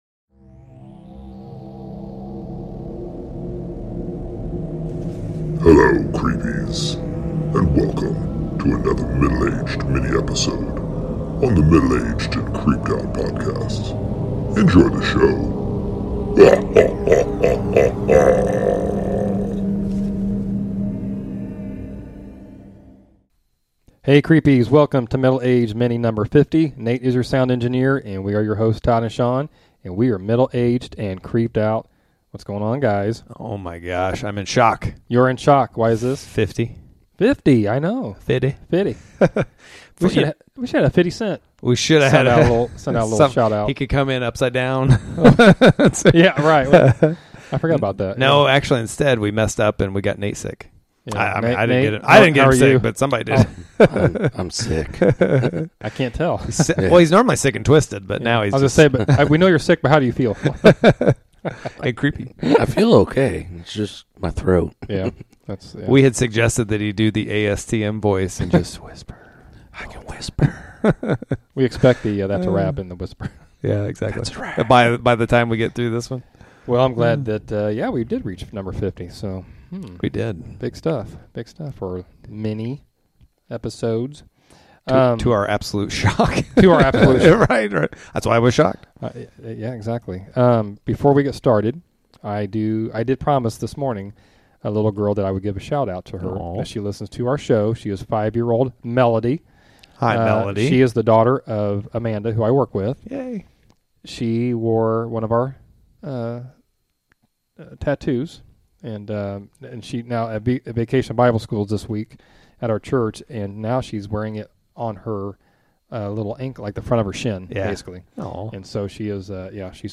The guys have a spooky and haunted talk about...Bachelor's Grove Cemetery (one the world's most haunted cemeteries)!!!